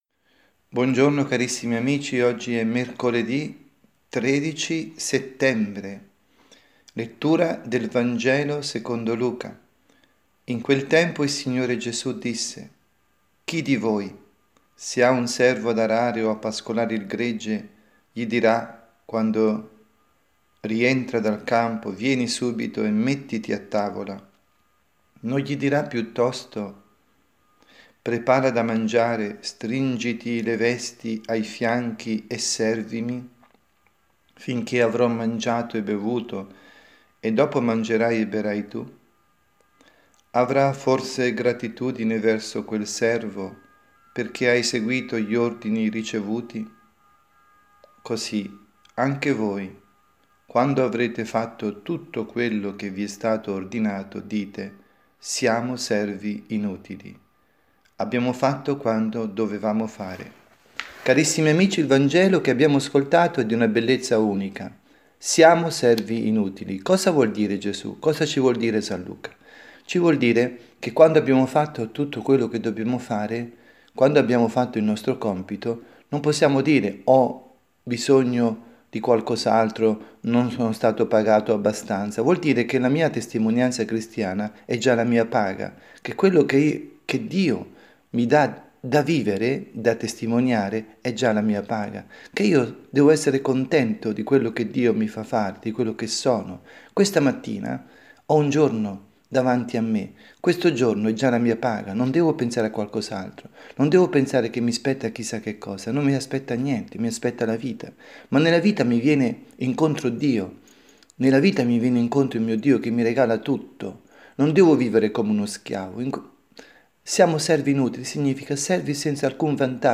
Catechesi
dalla Parrocchia S. Rita, Milano